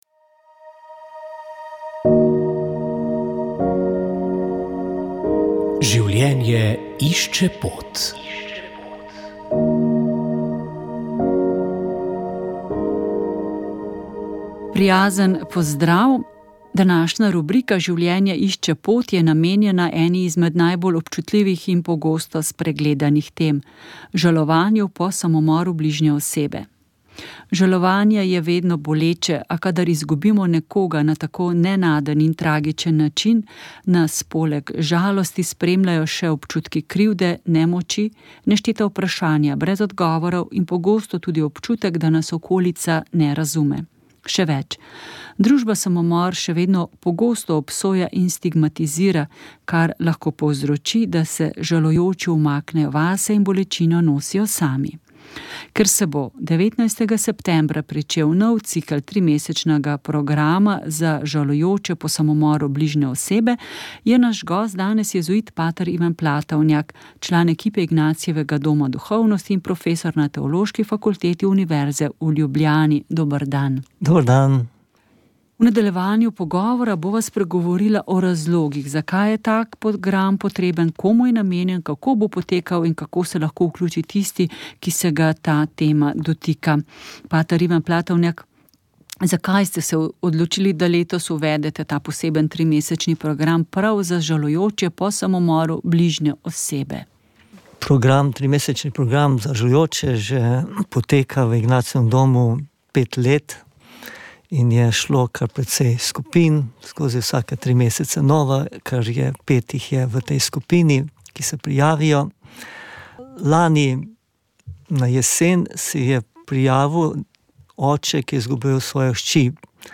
Molili so molilci pobude Molitev in post za domovino.